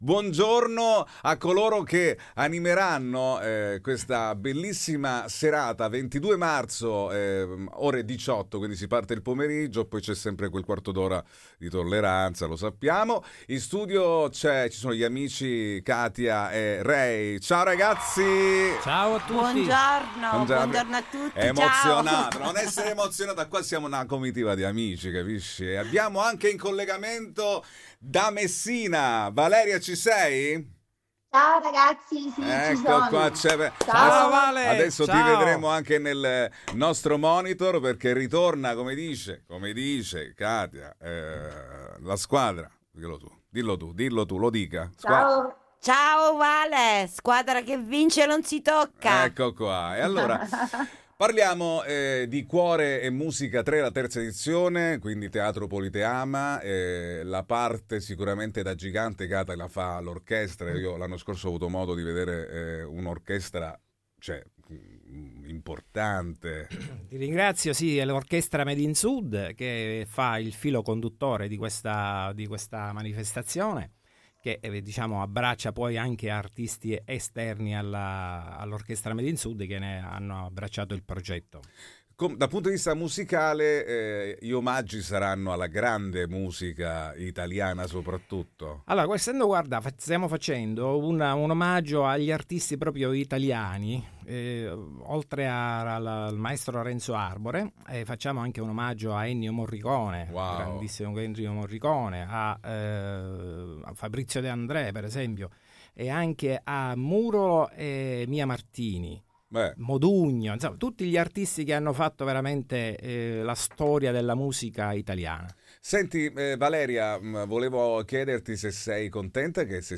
Interviste
intervista-all-inclusive.mp3